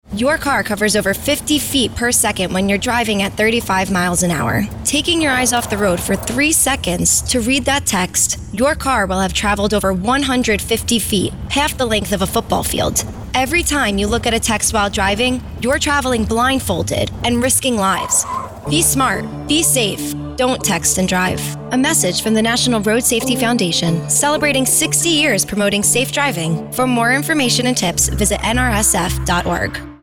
nrsf-distracted-driving-psa-texting.mp3